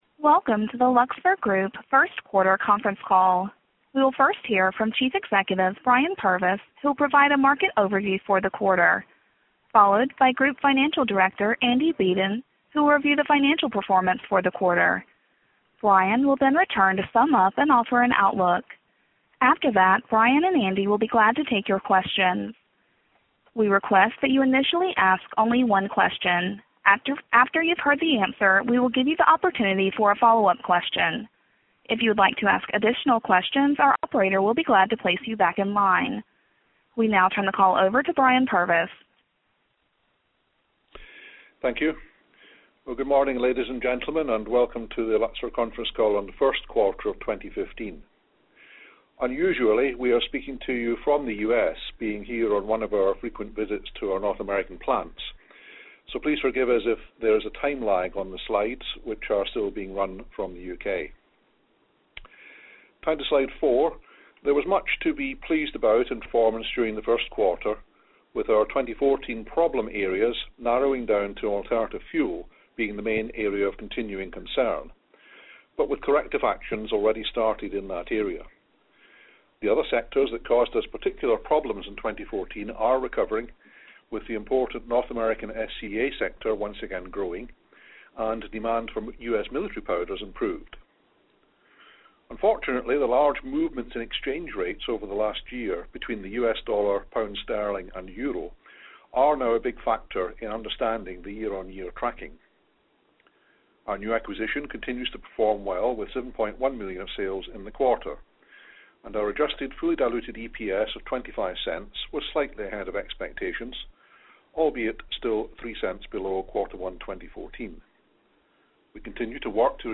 2015 Q1 Conference Call Audio (Opens in a new browser window)
q12015confcall.mp3